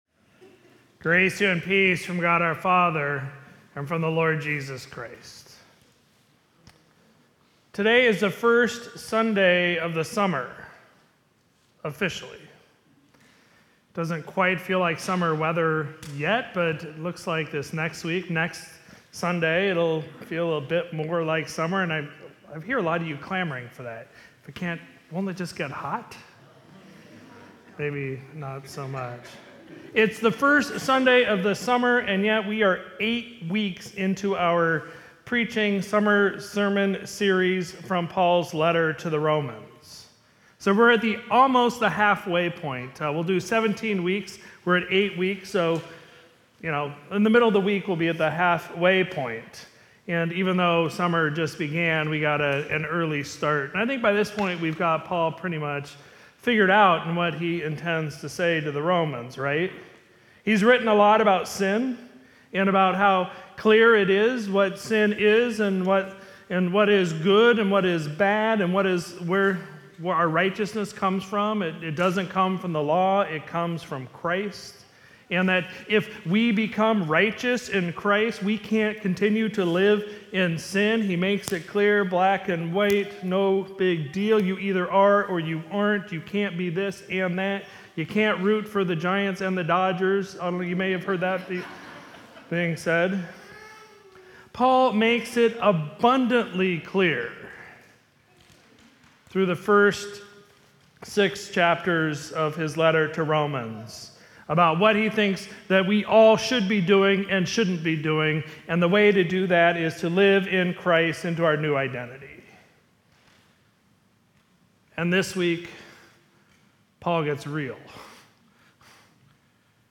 Sermon for Sunday, June 25, 2023